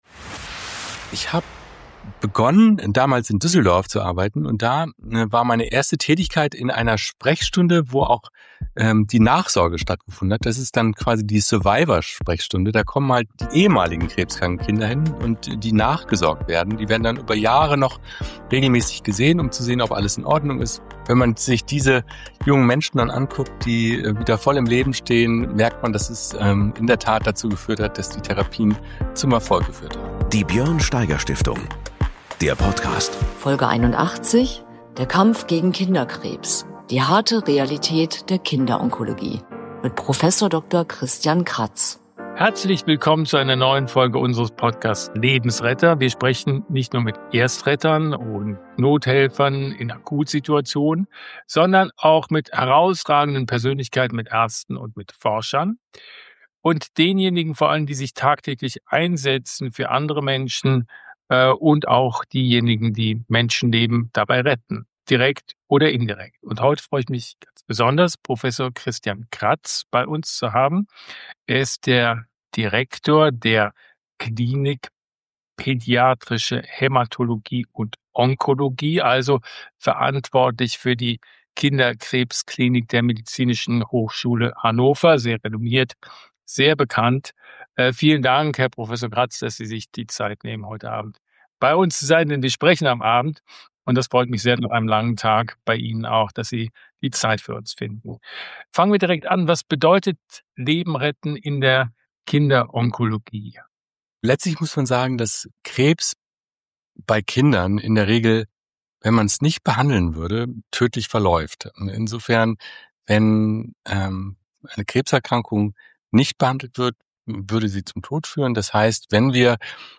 In diesem Podcast geht es nicht nur um die Arbeit von Erstrettern und Notfallhelfern in Akutsituationen, sondern Béla Anda spricht auch mit herausragenden Persönlichkeiten, Ärzten und Forschern, die sich tagtäglich einsetzen, um Menschenleben zu retten.